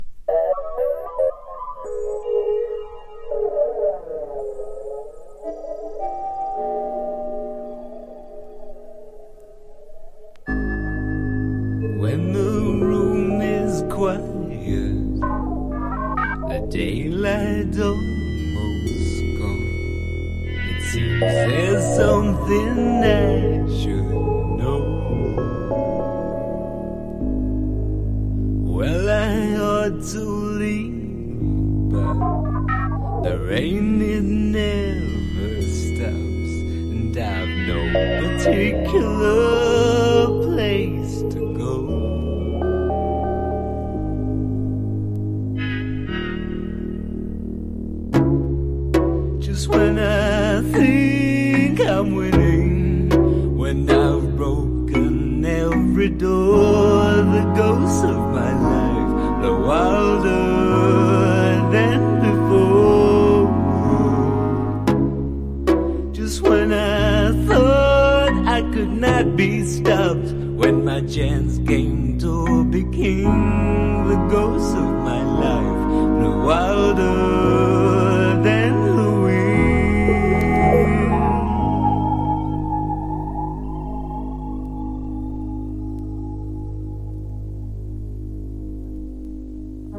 ダウナーな雰囲気が独特な